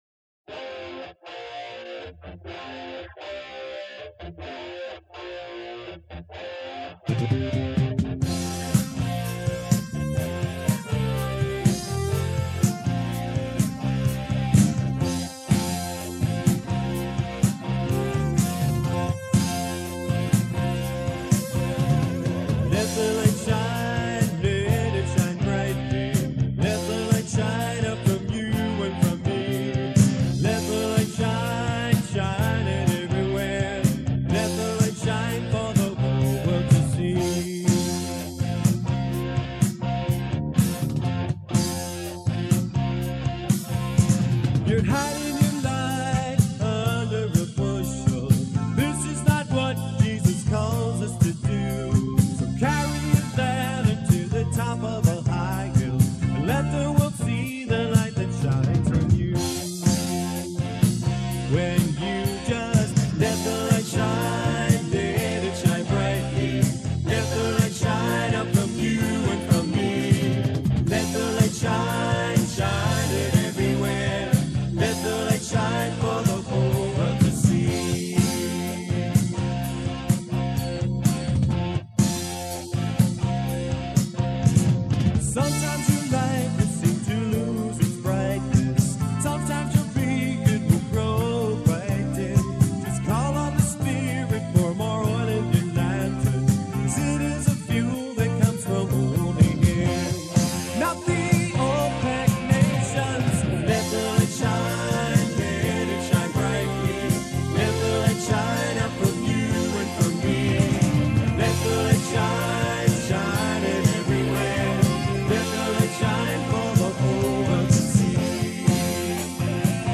Synthesizer on all selections
Bass guitar on all selections
String and synthesizer arrangements on all selections
Drums and percussion on all selections